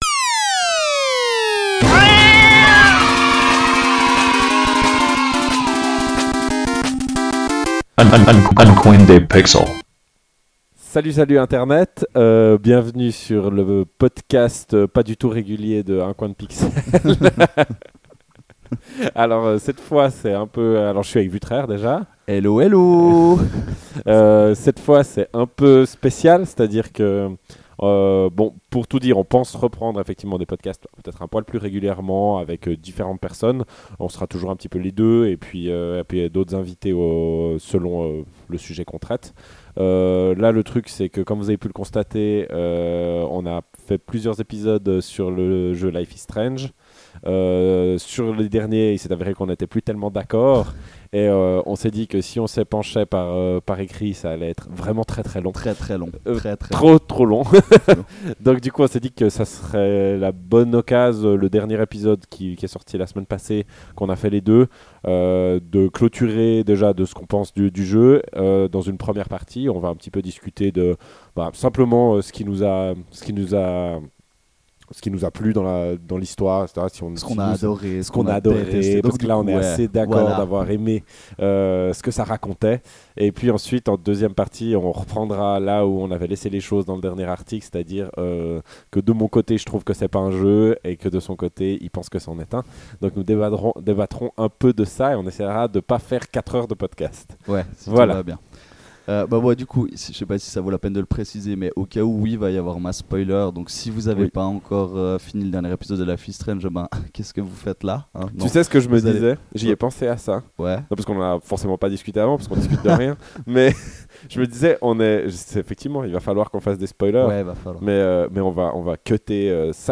Comme pour les autres épisodes, on en parle, on en débat.